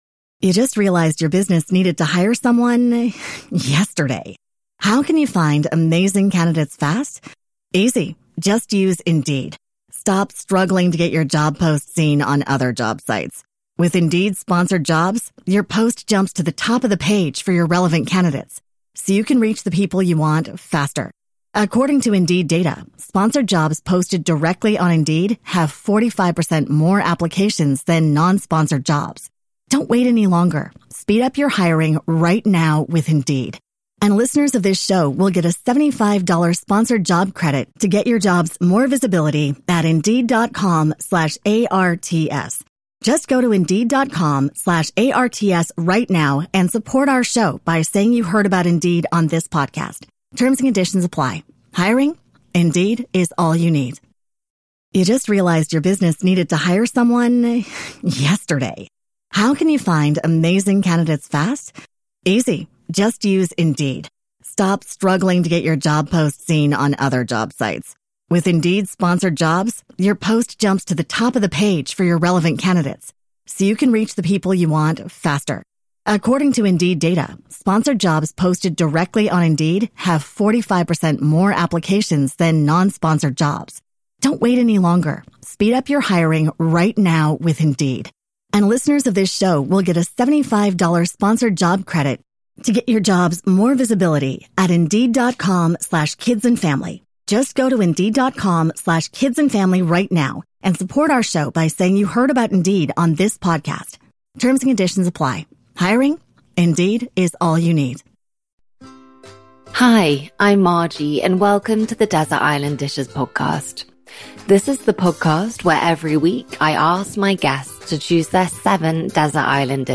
My guest today is Saliha Mahmood Ahmed
Please ignore my extreme English accent and I’m sure I pronounce things in an extreme anglicised way so I hope you forgive me and enjoy today’s episode!